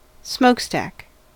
smokestack: Wikimedia Commons US English Pronunciations
En-us-smokestack.WAV